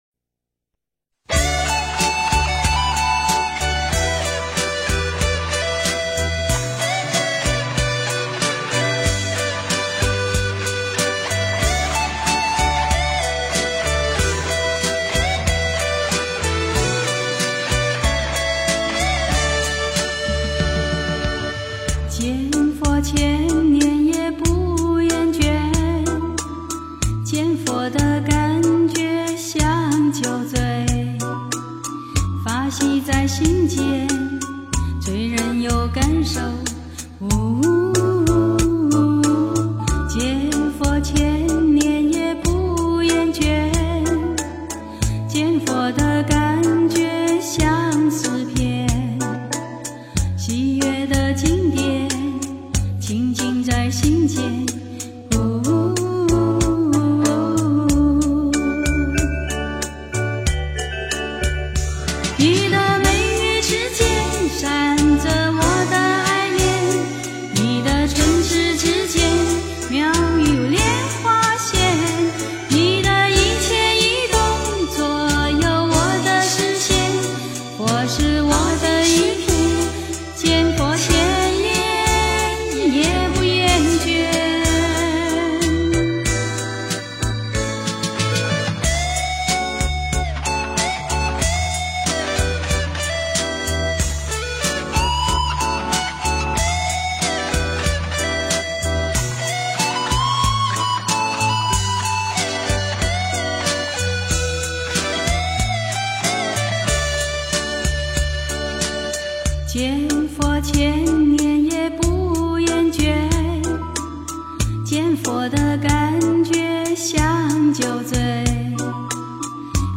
见佛千年不厌倦 诵经 见佛千年不厌倦--佛教音乐 点我： 标签: 佛音 诵经 佛教音乐 返回列表 上一篇： 佛陀 下一篇： 菩提树 相关文章 广陵散--古琴 广陵散--古琴...